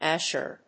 /ˈæʃɝ(米国英語), ˈæʃɜ:(英国英語)/